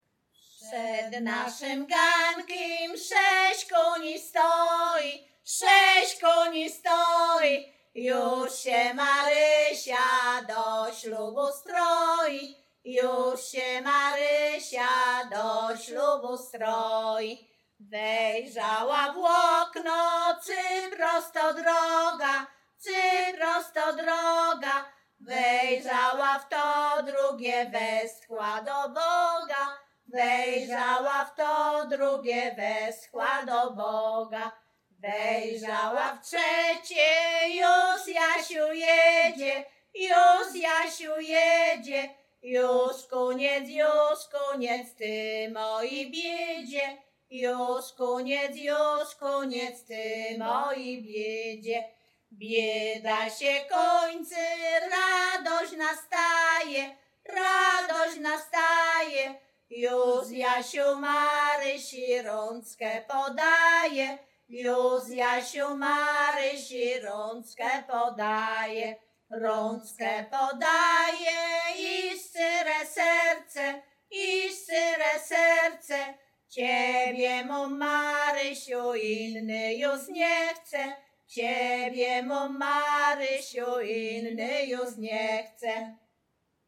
Śpiewaczki z Chojnego
województwo łódzkie, powiat sieradzki, gmina Sieradz, wieś Chojne
Weselna